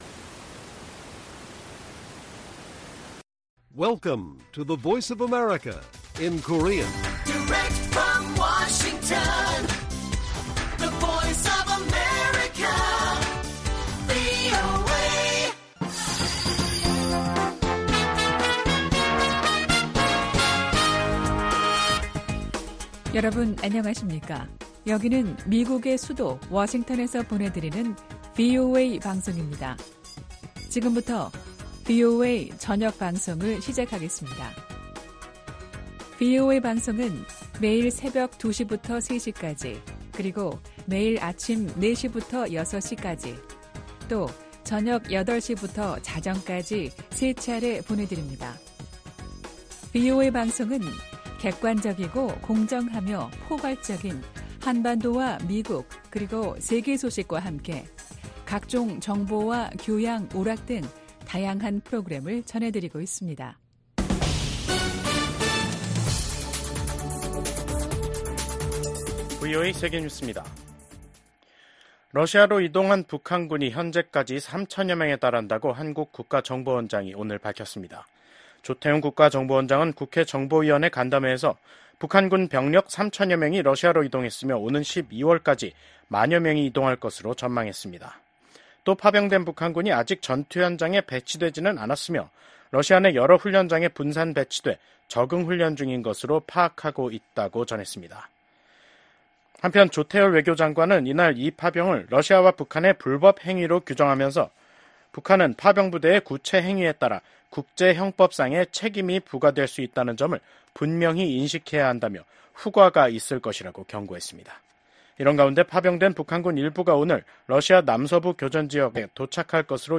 VOA 한국어 간판 뉴스 프로그램 '뉴스 투데이', 2024년 10월 23일 1부 방송입니다. 국무부는 한국이 우크라이나에 무기지원을 검토할 수 있다고 밝힌 데 대해 모든 국가의 지원을 환영한다는 입장을 밝혔습니다. 미국 하원의원들이 북한이 러시아를 지원하기 위해 특수부대를 파병했다는 보도에 깊은 우려를 표했습니다.